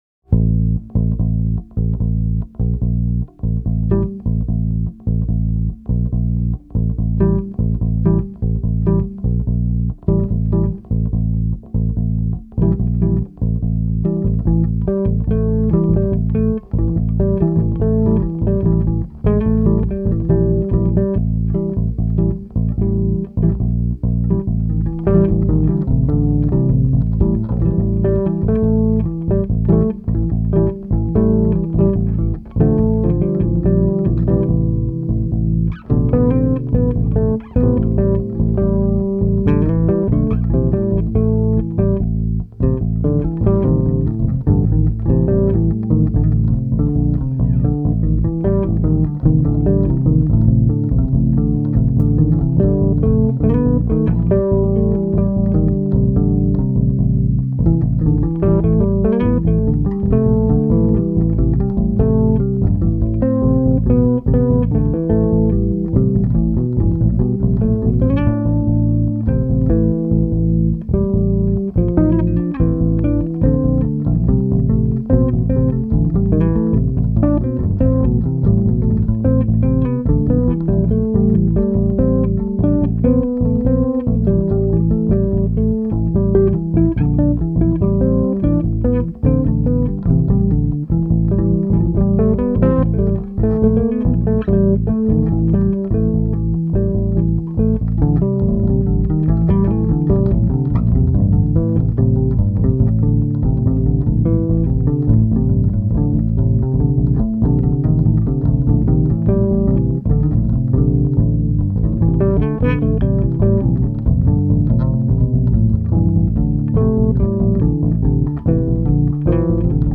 bassoon/clarinet/sax
guitars/keyboard/vocals
drums/percussion